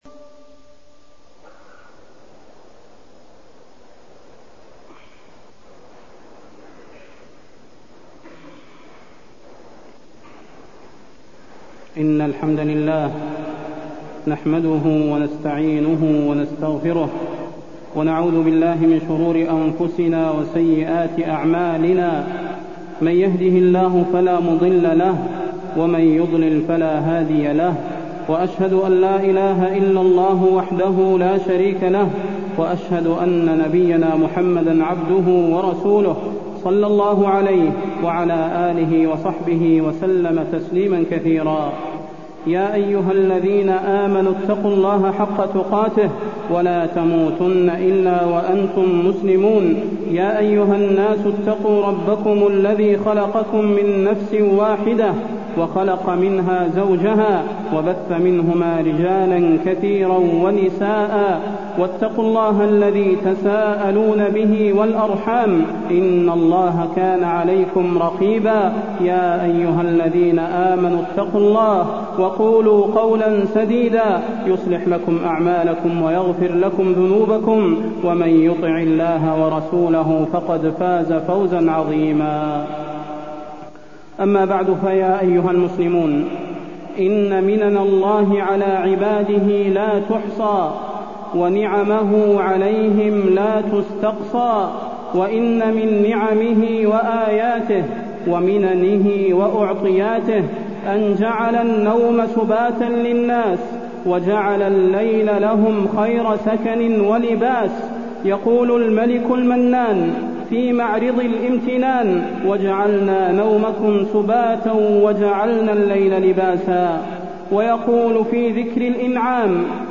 تاريخ النشر ١٧ ربيع الثاني ١٤٢٣ هـ المكان: المسجد النبوي الشيخ: فضيلة الشيخ د. صلاح بن محمد البدير فضيلة الشيخ د. صلاح بن محمد البدير السهر ومضاره